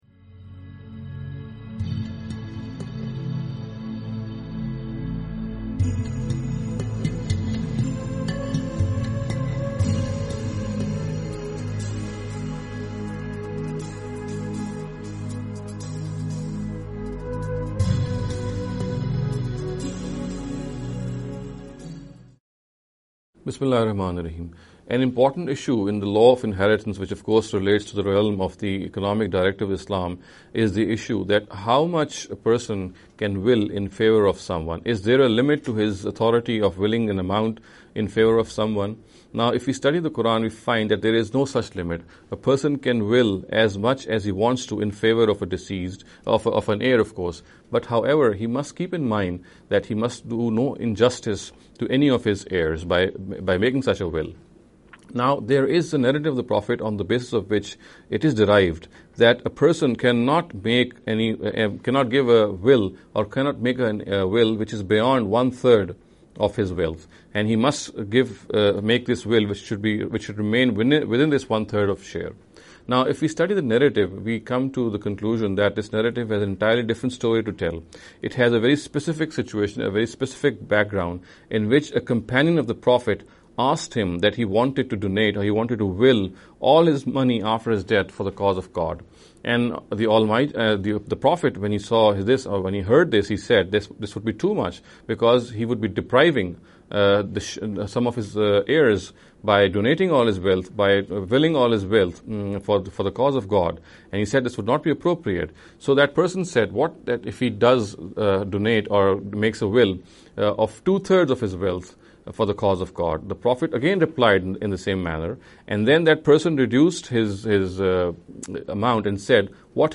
This lecture series will deal with some misconception regarding the Economic Directives of Islam. In every lecture he will be dealing with a question in a short and very concise manner. This sitting is an attempt to deal with the question 'Can a Will be Made Beyond One Third of the legacy?’.